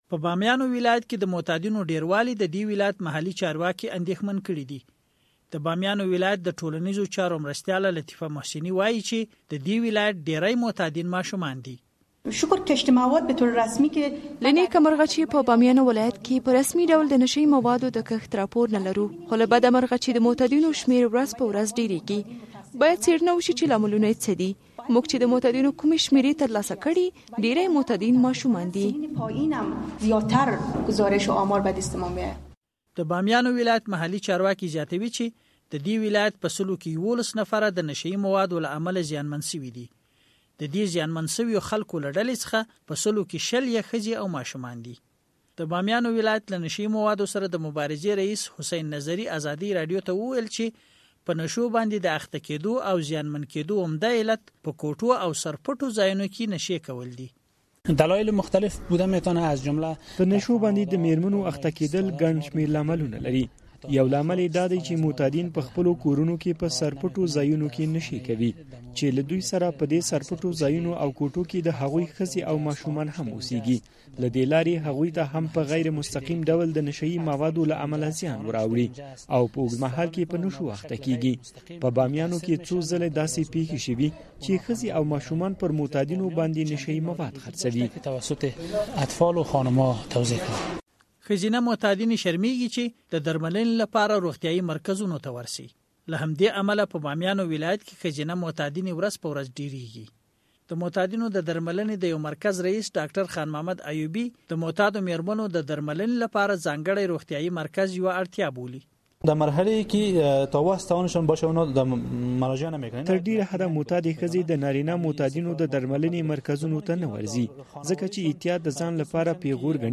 د بامیان راپور